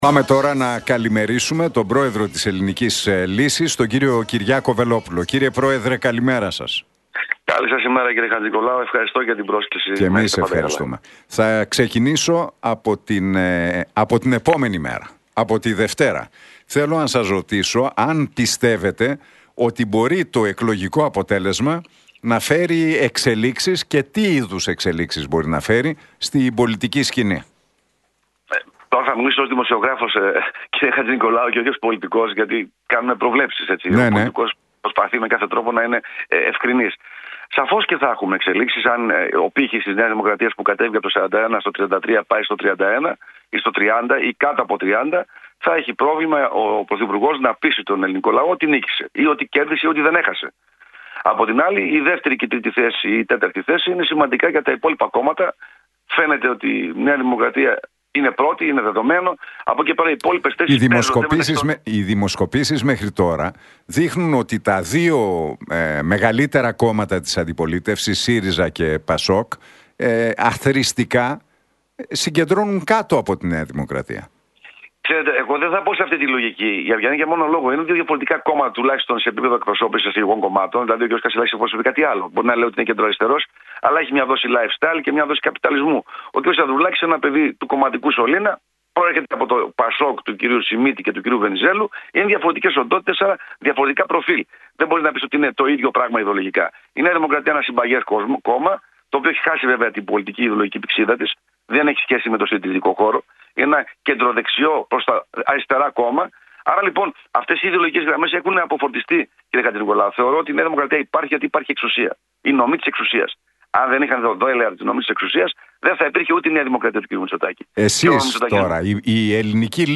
Για τις Ευρωεκλογές της Κυριακής, αλλά και για την ακρίβεια και τα εθνικά θέματα μίλησε μεταξύ άλλων ο πρόεδρος της Ελληνικής Λύσης, Κυριάκος Βελόπουλος μιλώντας στον Realfm 97,8 και την εκπομπή του Νίκου Χατζηνικολάου.